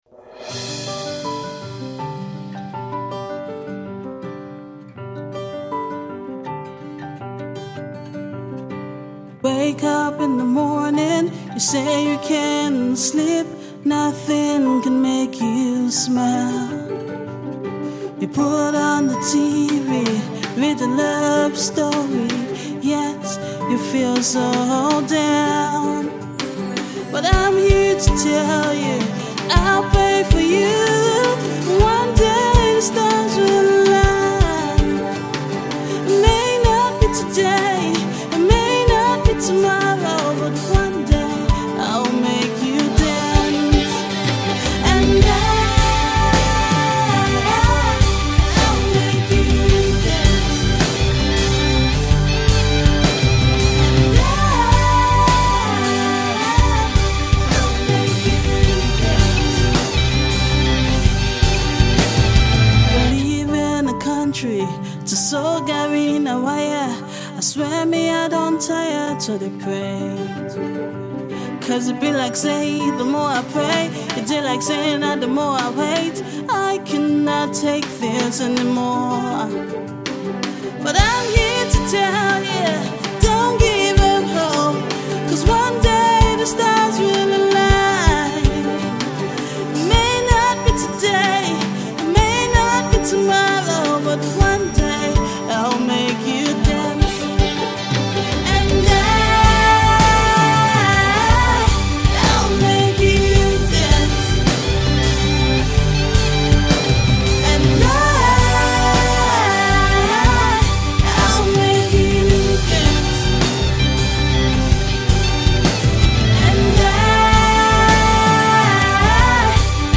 In the worlds of Indie, Classic and Pop Rock